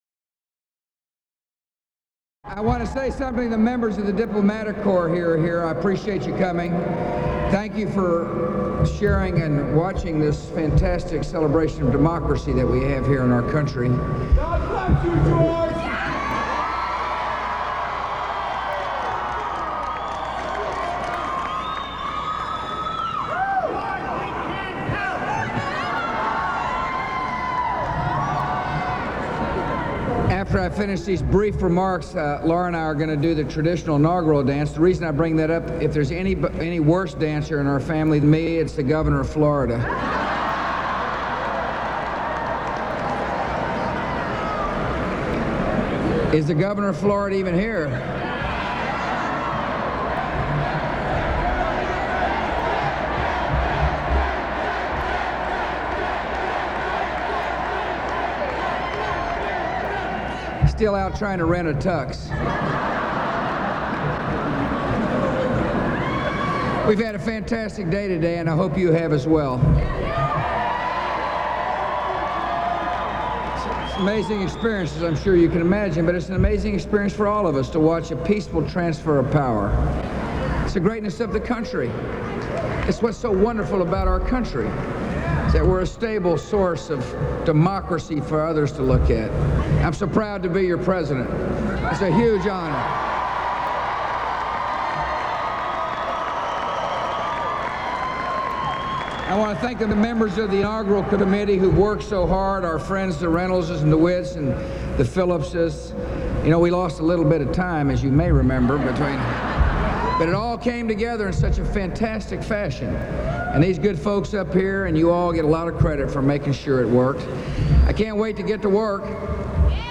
U.S. President George W. Bush speaks at the Florida inaugural ball